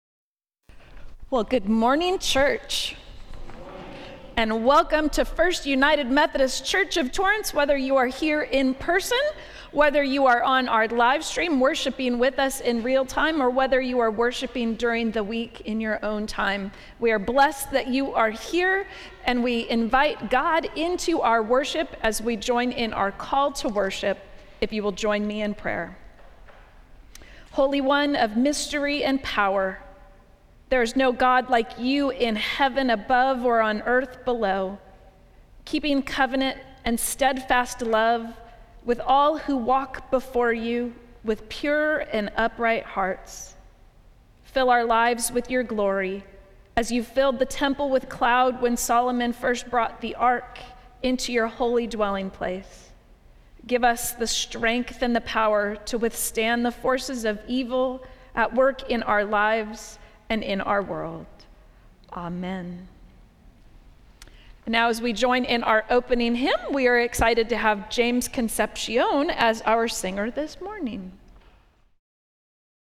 Service of Worship
Welcome and Opening Prayer